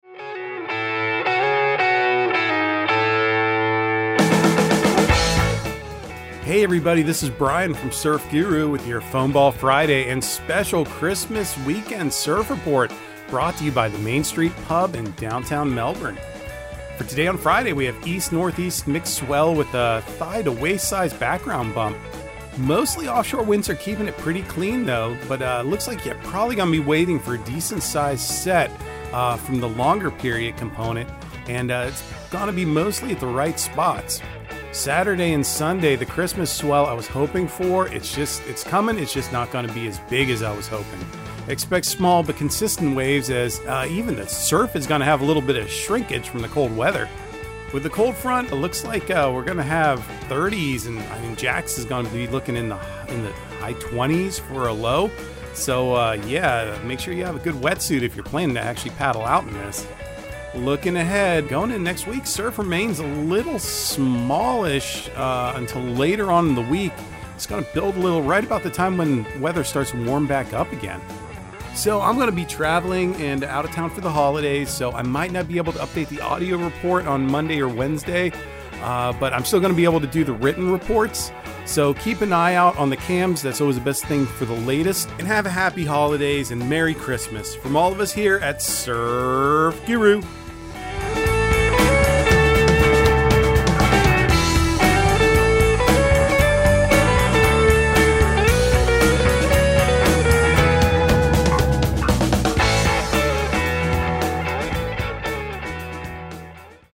Surf Guru Surf Report and Forecast 12/23/2022 Audio surf report and surf forecast on December 23 for Central Florida and the Southeast.